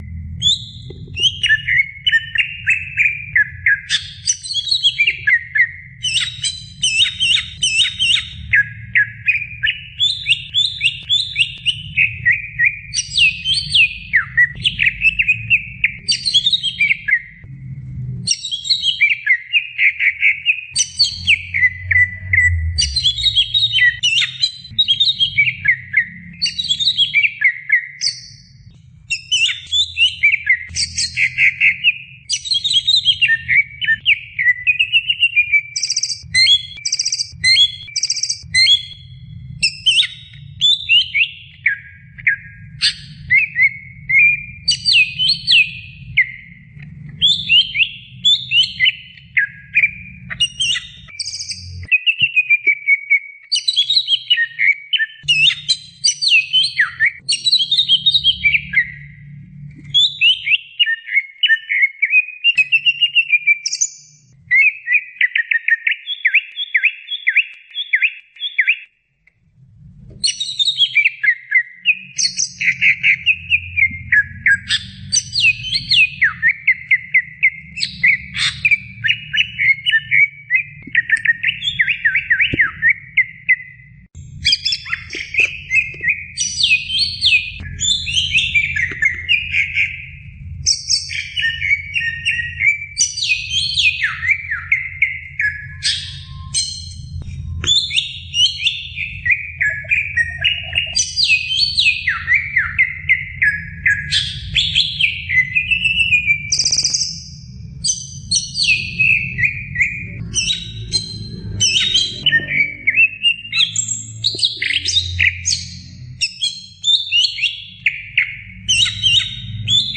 Suara Cucak Ijo Juara 1 Nasional
Kategori: Suara burung
Keterangan: Suara masteran cucak ijo pilihan juri juara 1 nasional dan internasional, full isian jernih, cocok untuk latihan burung.
suara-cucak-ijo-juara-1-nasional-id-www_tiengdong_com.mp3